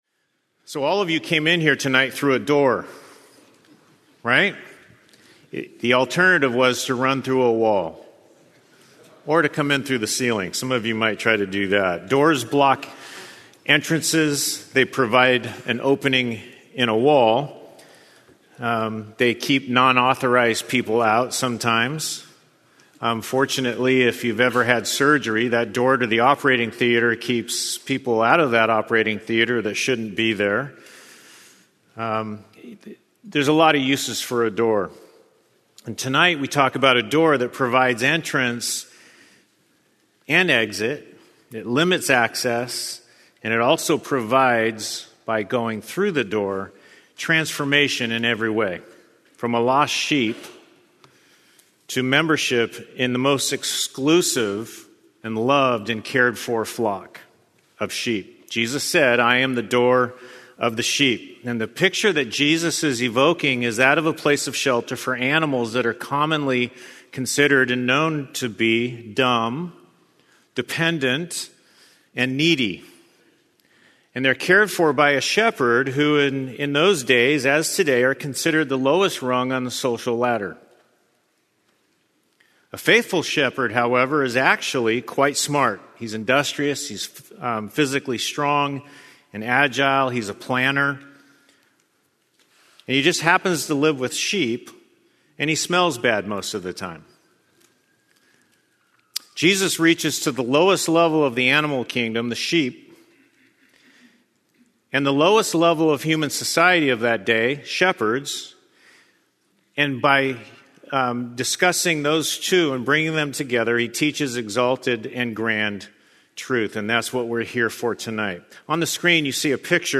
*Due to technical difficulties the video begins two minutes in*